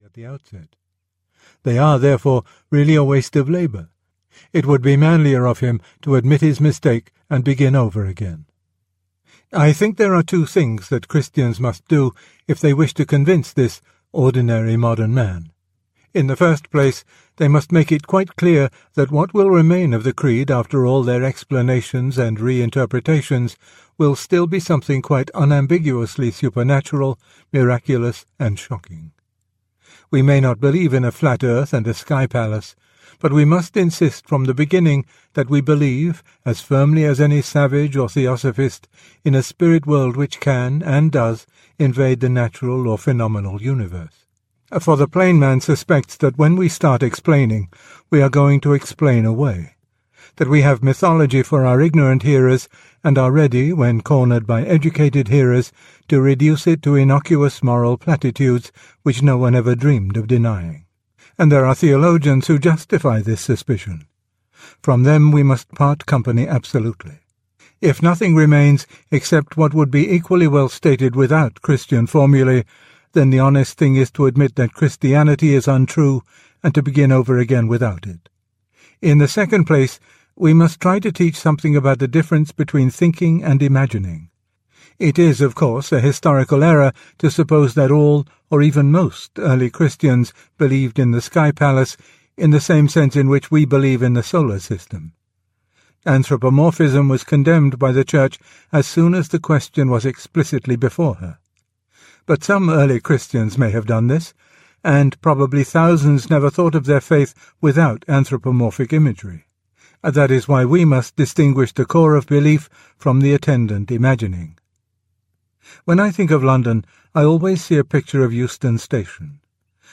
Aspects of Faith Audiobook
9.5 Hrs. – Unabridged